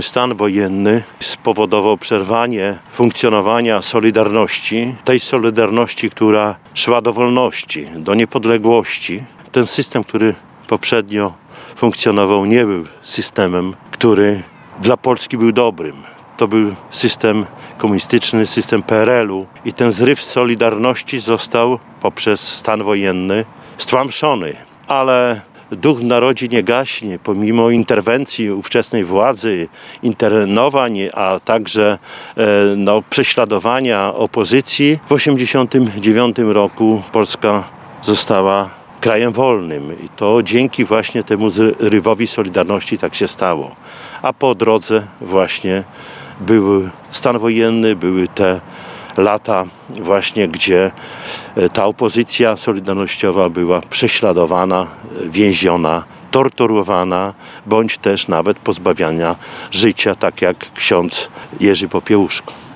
W związku z przypadającą dziś 39. rocznicą wprowadzenia w Polsce stanu wojennego miejska delegacja złożyła kwiaty pod Dębem Wolności w Parku Konstytucji 3 Maja. Podczas okolicznościowego wystąpienia głos zabrał Czesław Renkiewicz, prezydent Suwałk.